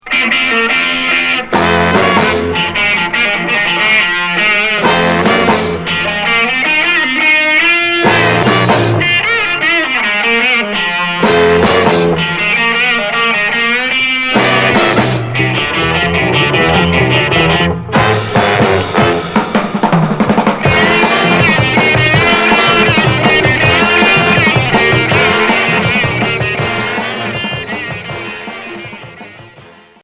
R'n'b garage